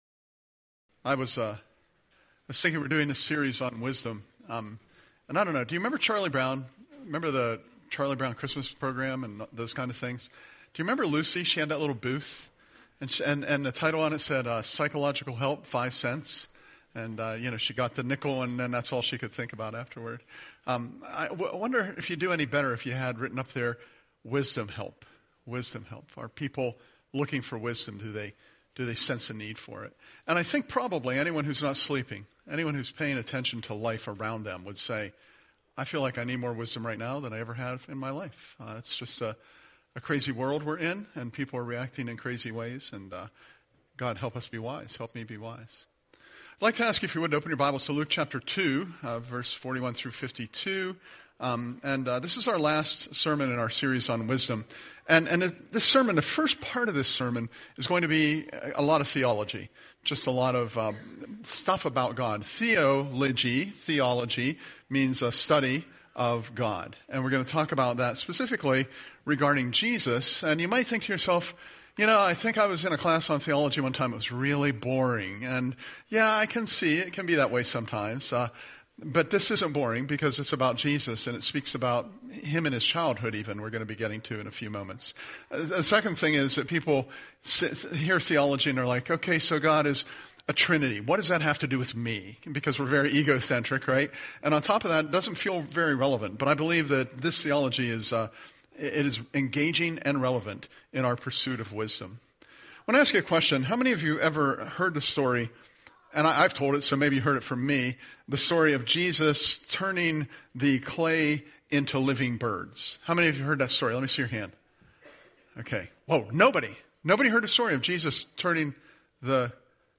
Presented at Curwensville Alliance on 2/1/26